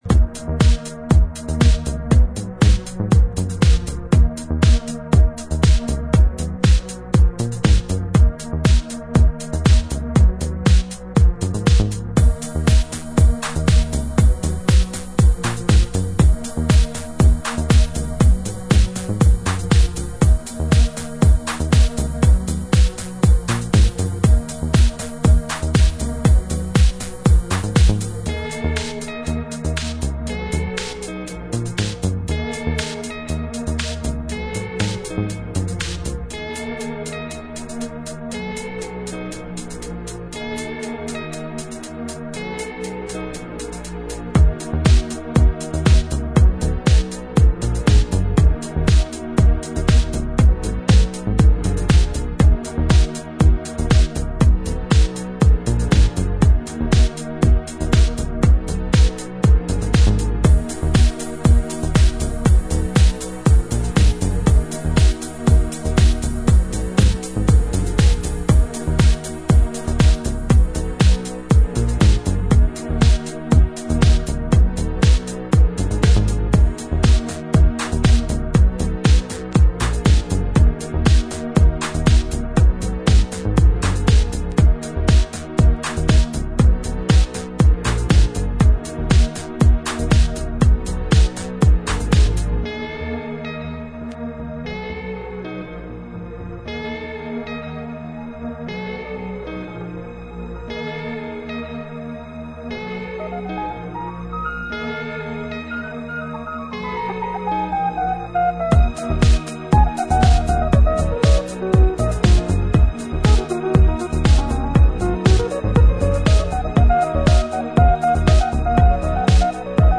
ウッド・ベース等のジャジーな要素を取り入れた渋いディープ・ハウス！